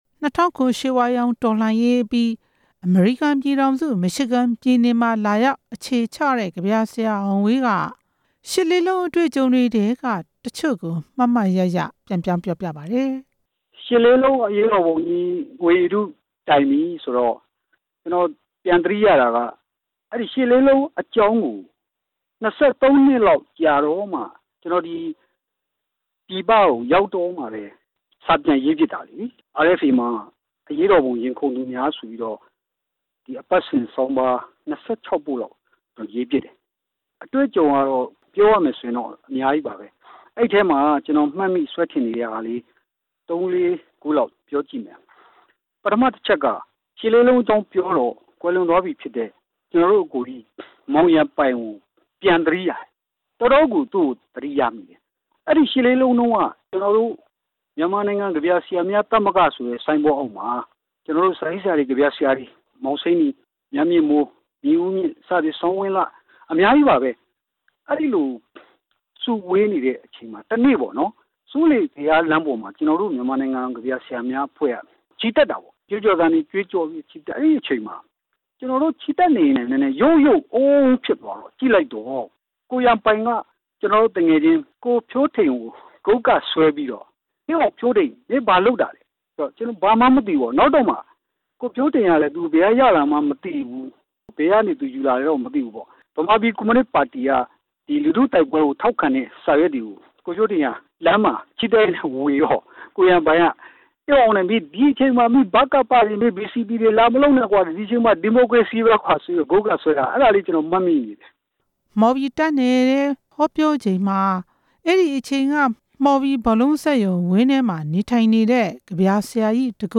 ဆက်သွယ်မေးမြန်း တင်ပြထားပါတယ်။